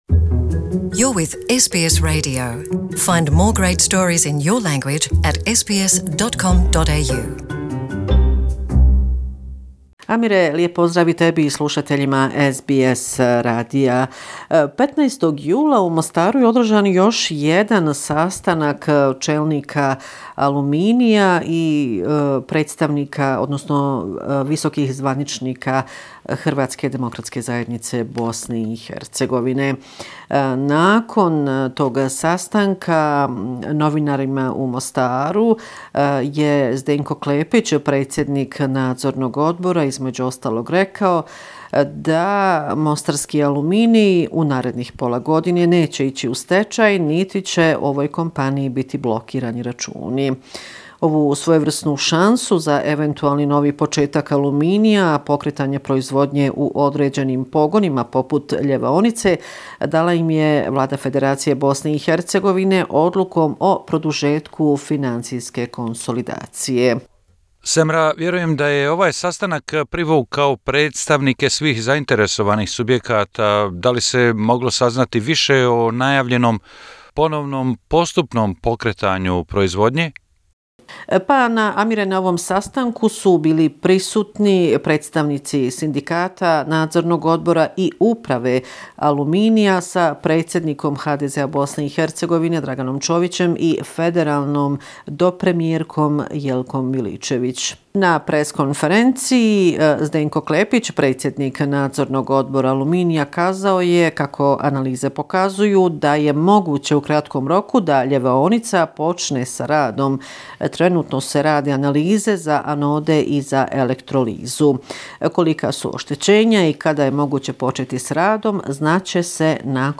Sedmični izvještaj iz Bosne i Hercegovine